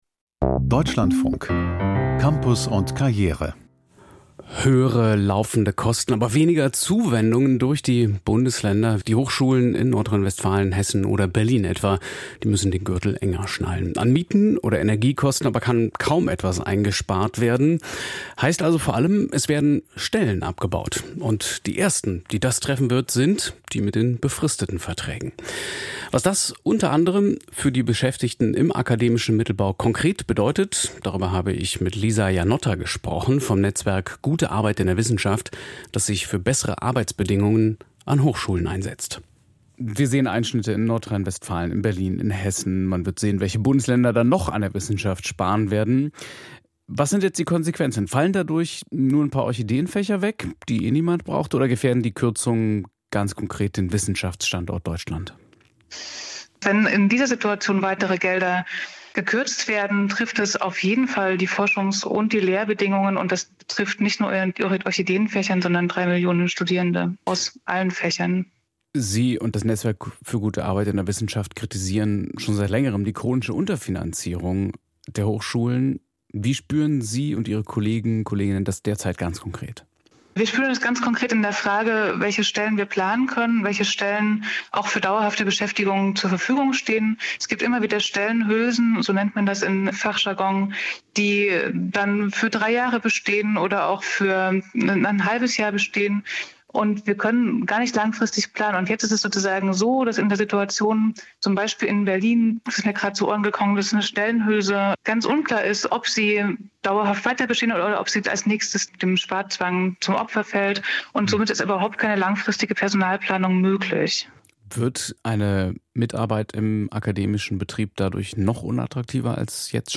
Kürzungen: Akademischer Nachwuchs protestiert leise - Interview